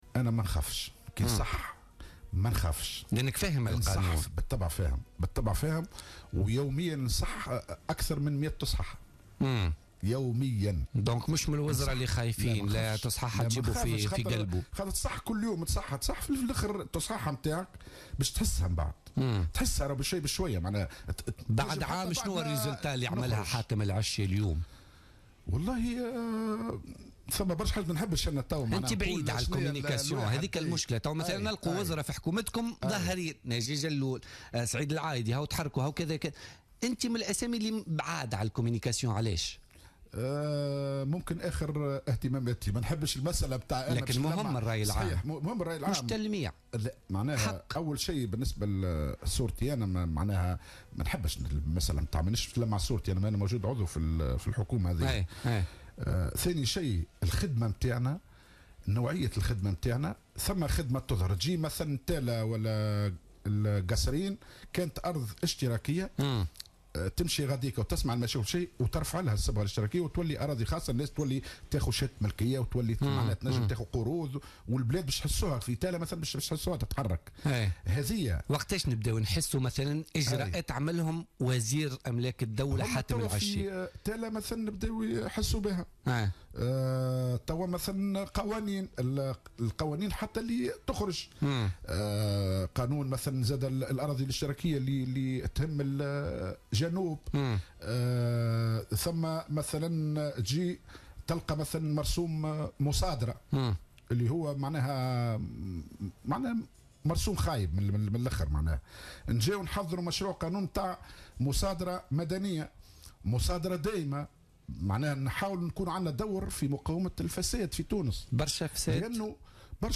أكد وزير أملاك الدولة والشؤون العقارية حاتم العشي ضيف بوليتيكا اليوم الجمعة 18 مارس 2016 أن الإشكالات العقارية القائمة اليوم في تونس هي السبب في تعطيل المشاريع التنموية.